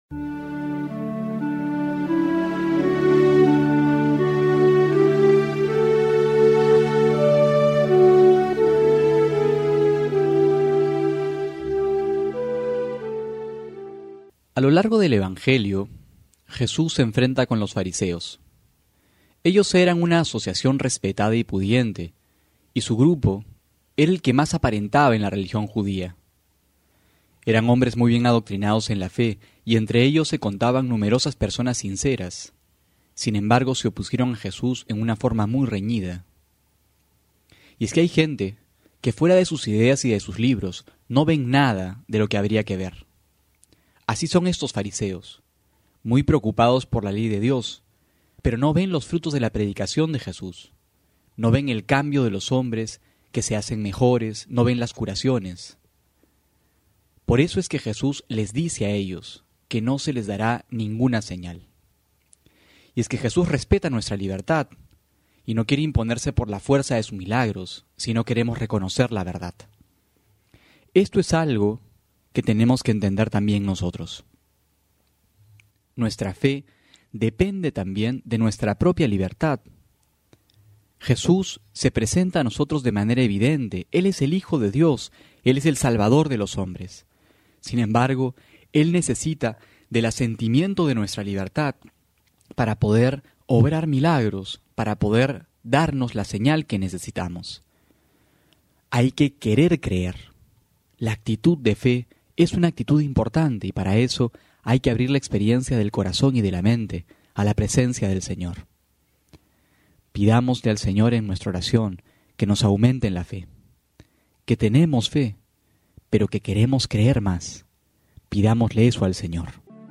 Homilía para hoy: Marcos 8,11-13
febrero13-12homilia.mp3